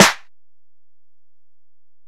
Snare (50).wav